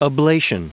Prononciation du mot oblation en anglais (fichier audio)
Prononciation du mot : oblation